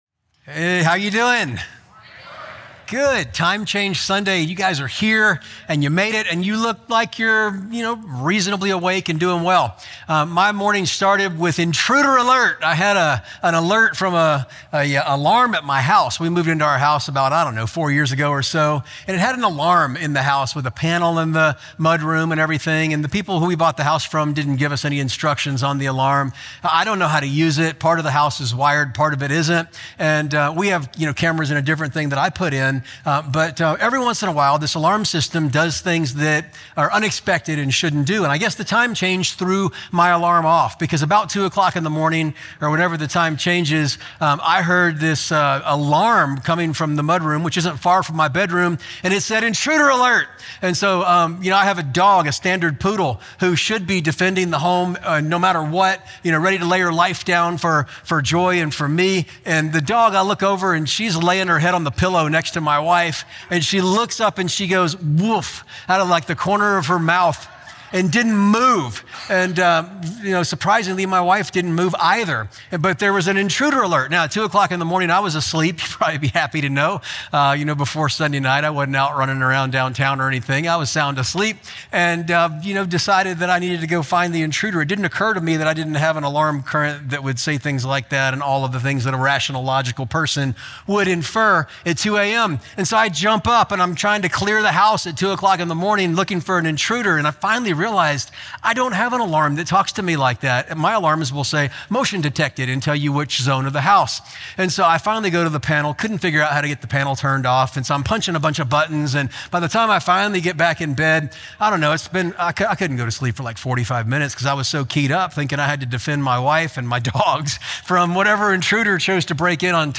Sermon Only – Short Version